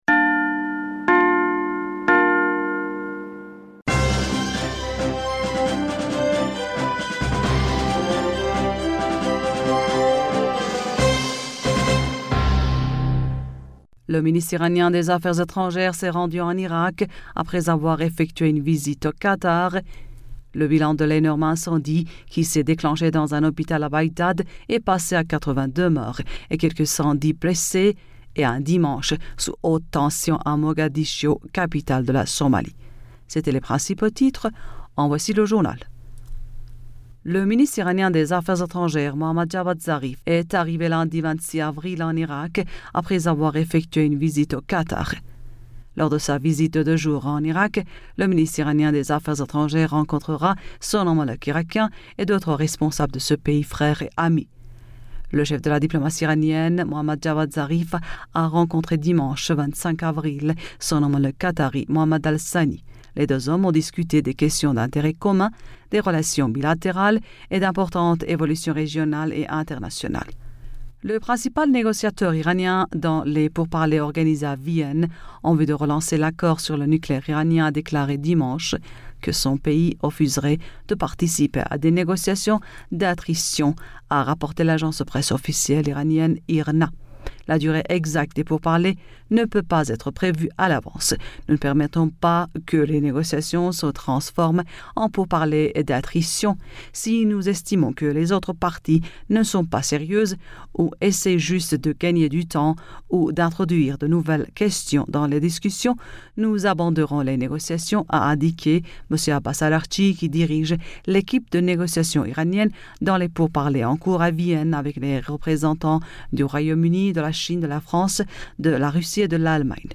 Bulletin d'information du 26 Avril 2021